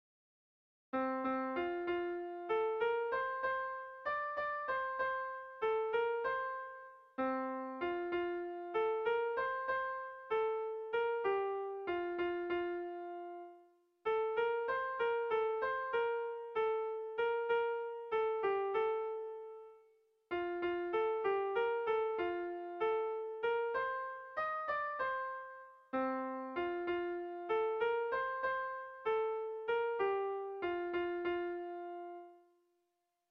Kontakizunezkoa
Biriatu < Lapurdi Itsasegia < Lapurdi < Euskal Herria
Hamarreko txikia (hg) / Bost puntuko txikia (ip)
A1A2BDA2